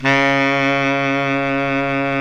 SAX B.SAX 05.wav